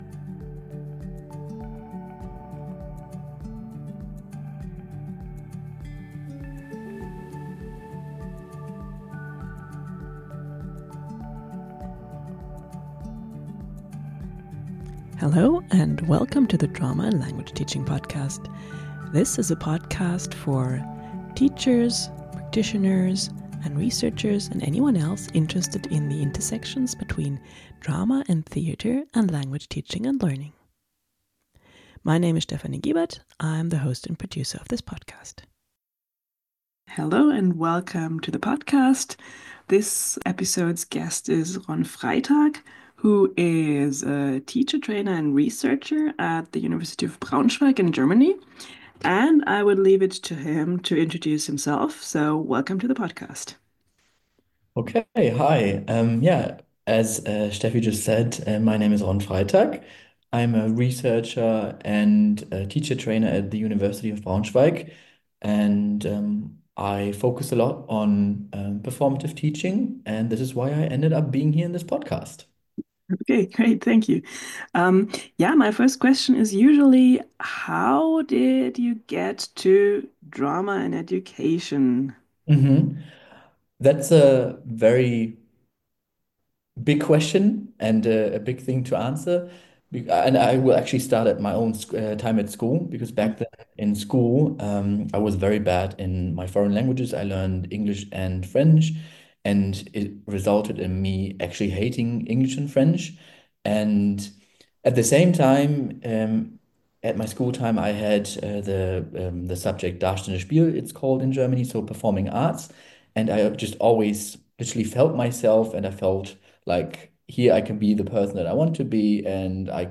In this episode I interview